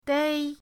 dei1.mp3